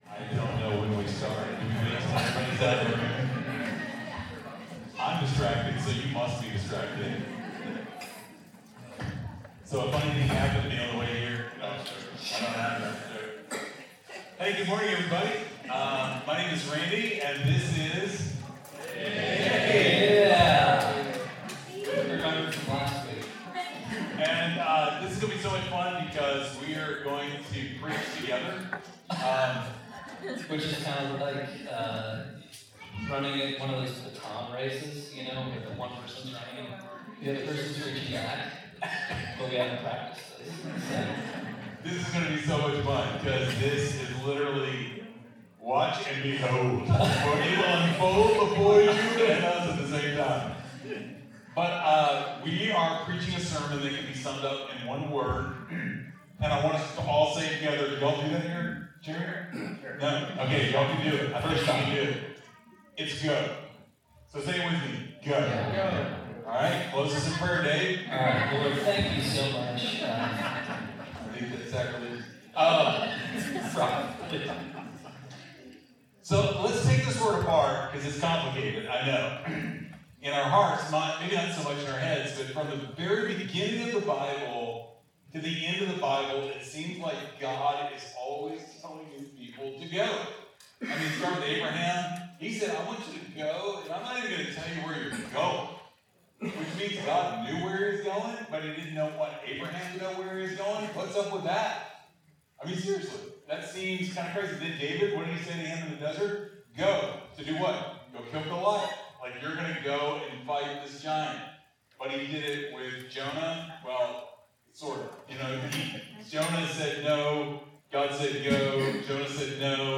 Midtown Fellowship Crieve Hall Sermons Going on Mission Mar 23 2025 | 00:48:41 1x 00:00 / 00:48:41 Subscribe Share Apple Podcasts Spotify Overcast RSS Feed Share Link Embed